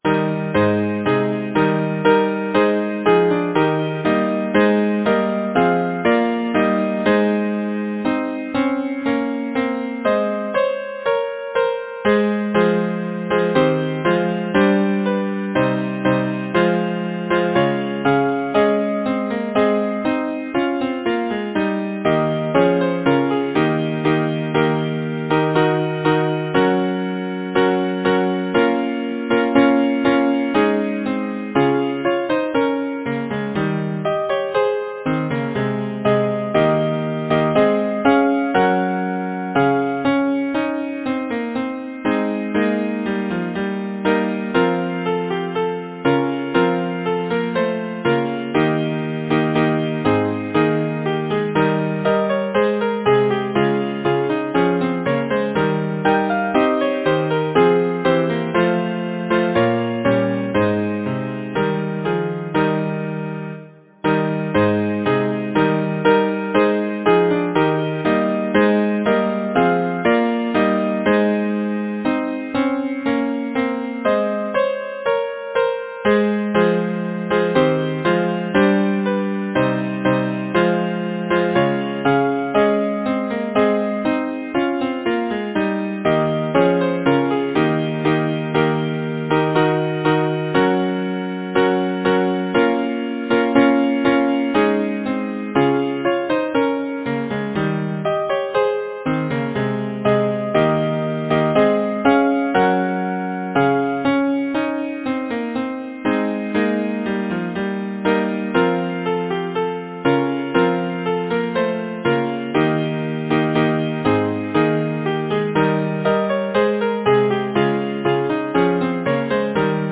Language: English Instruments: A cappella
First published: 1900 J. Curwen & Sons Description: Madrigal